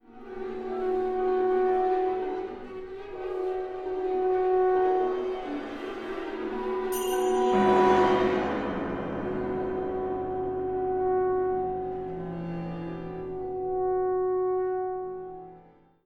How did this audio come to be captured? UCSD⇔AUA Composers Concert (Jun 2015, AUA chamber music hall)